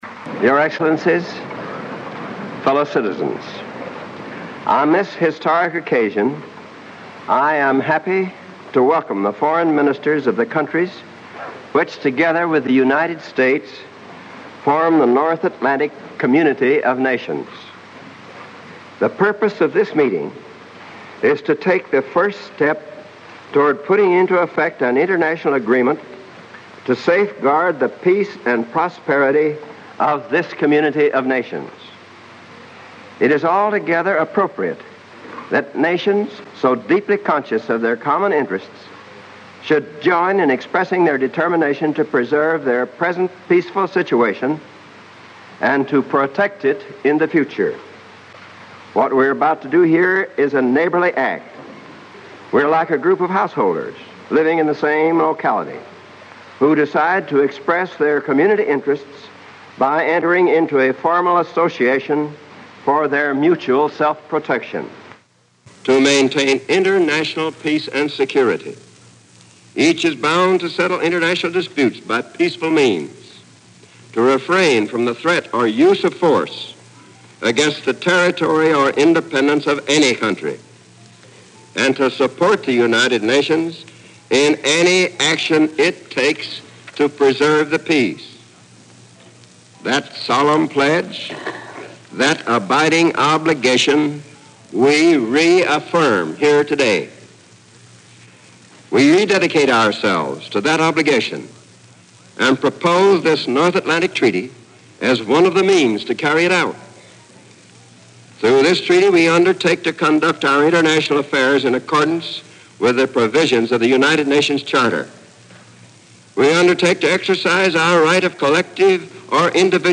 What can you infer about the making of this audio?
Address at the NATO Treaty Signing Ceremony delivered 4 April 1949, Departmental Auditorium, Washington, D.C.